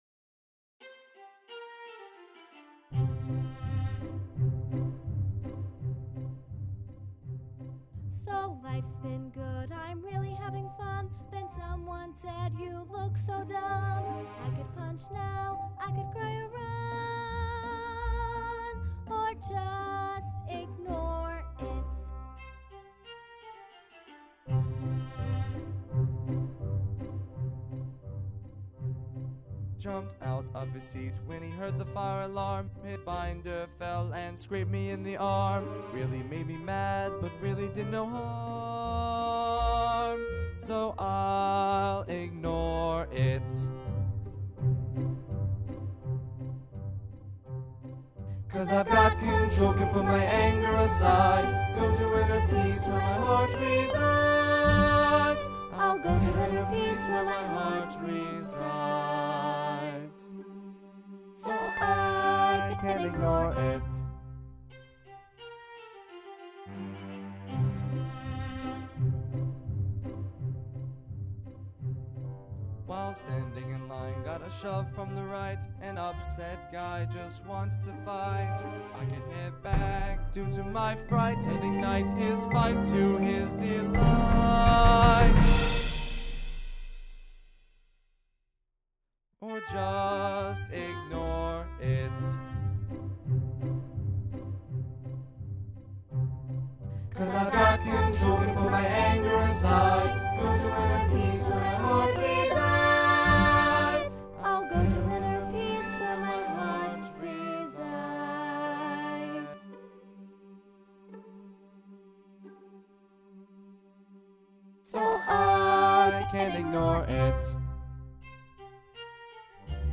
MP3 (with singing)